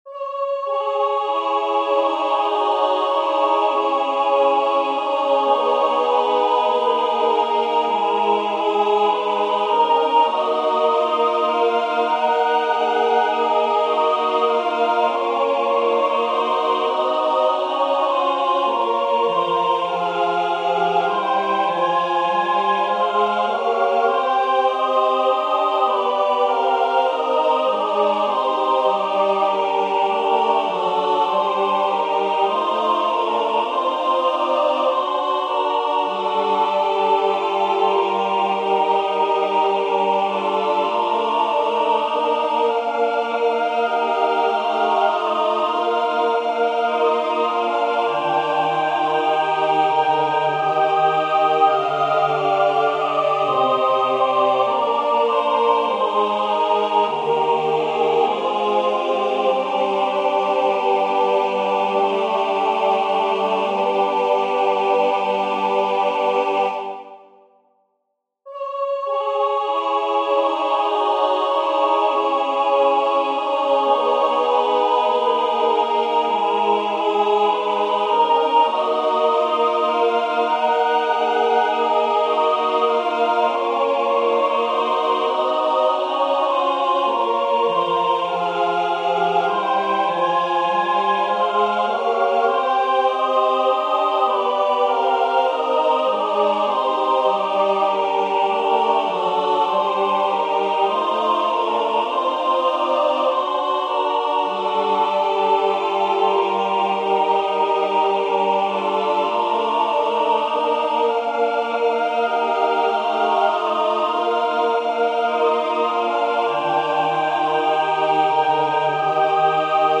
Non-Religious Christmas Songs